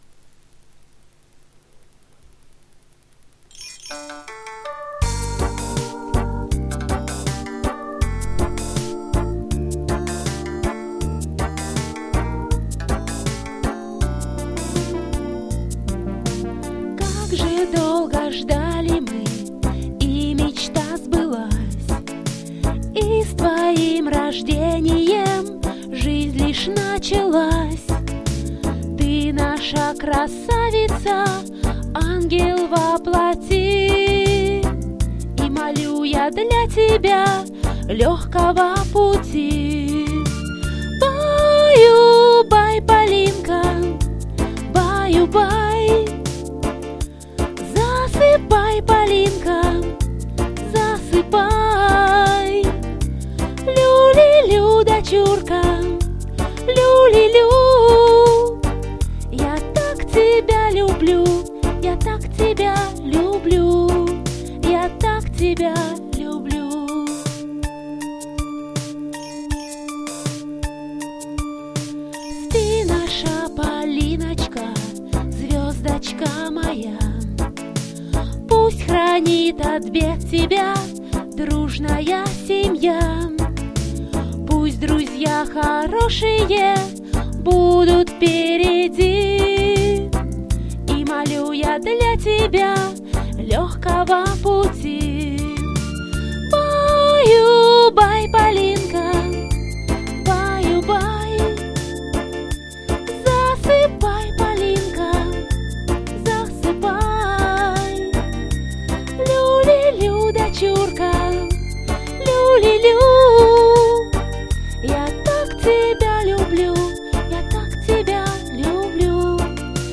Необходимо сочинить колыбельную для своего малыша, переложить на музыку (сочиненную вами или любую известную фонограмму), записать ее в вашем исполнении или исполнении мужа.
Колыбельная